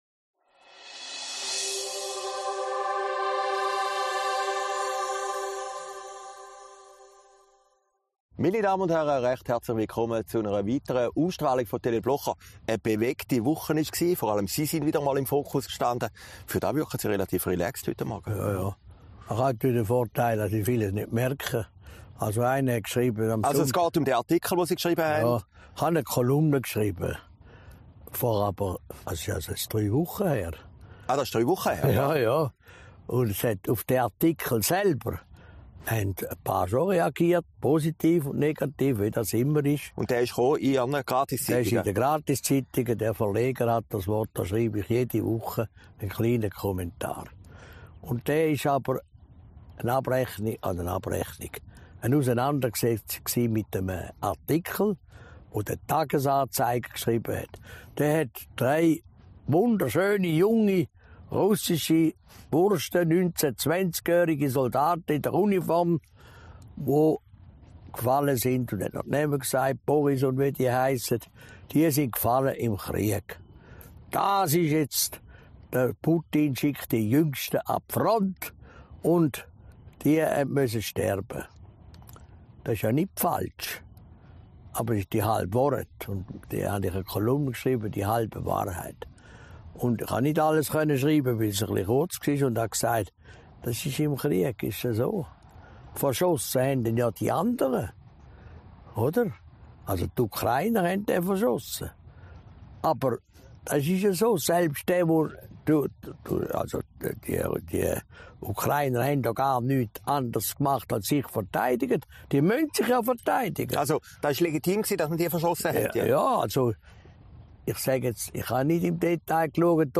August 2022, aufgenommen in Herrliberg